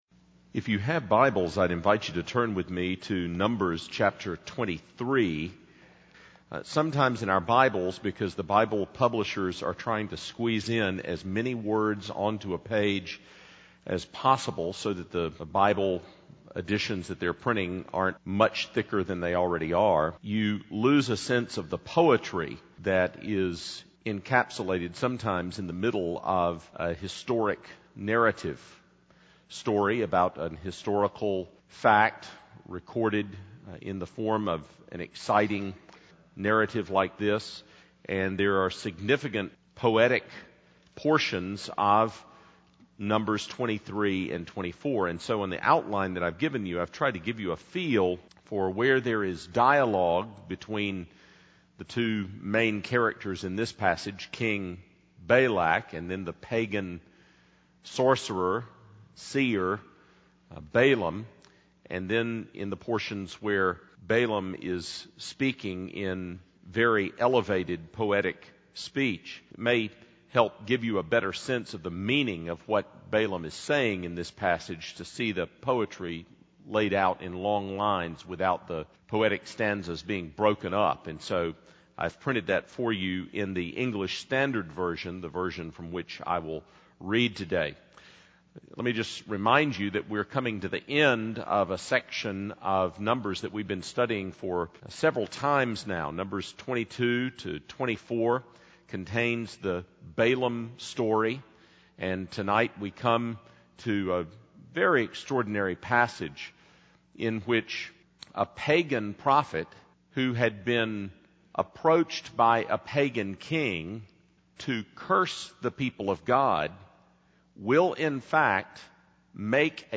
Sermon by on January 9, 2008 — Numbers 23:27-24:25